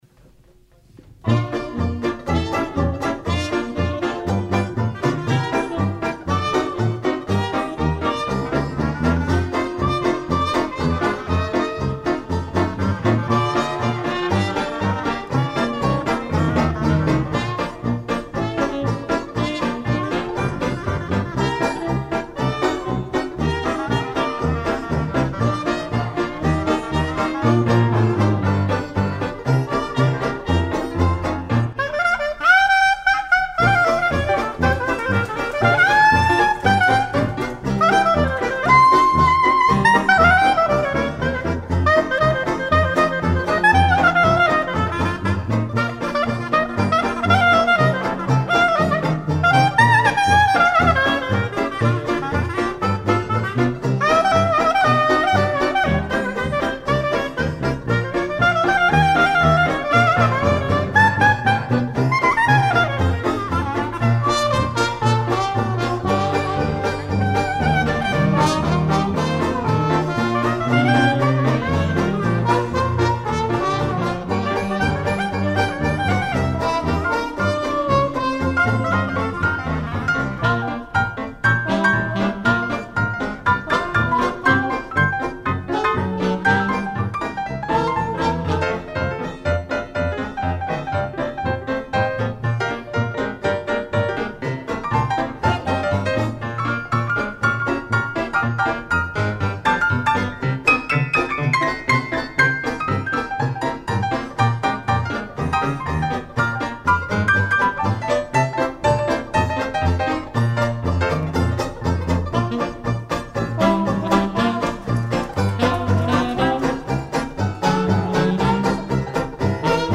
Soirée Années Folles
Spécialistes du jazz des années 20 et 30
piano
cornet
clarinettes, saxophones
banjo
tuba